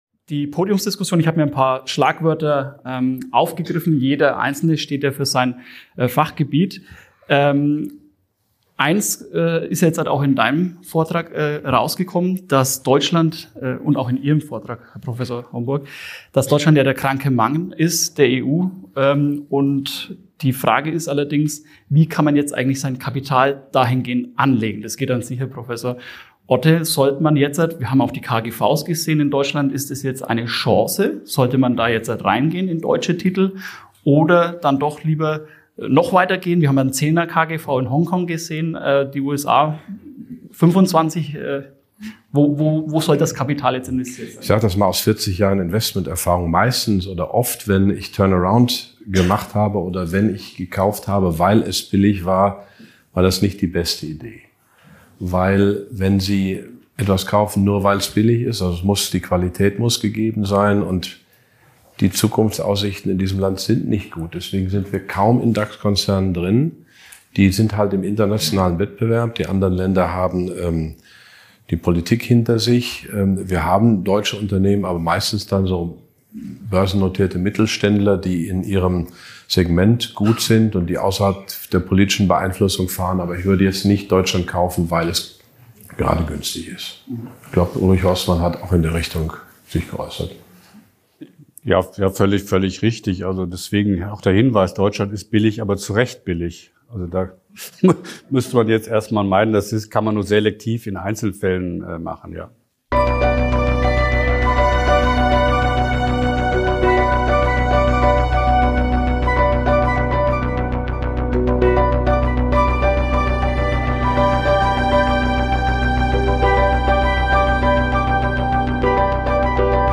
Sie diskutieren Deutschlands Wirtschaftslage, globale Investmentstrategien, Inflation, EZB-Politik und potenzielle Wirtschaftsreformen. Themen wie Staatsverschuldung, Modern Monetary Theory und die wirtschaftlichen Folgen der Corona-Pandemie werden ebenfalls behandelt. Die Experten bieten kontroverse Standpunkte und beantworten Fragen aus dem Publikum, was einen umfassenden Einblick in die aktuellen wirtschaftlichen und finanzpolitischen Debatten ermöglicht.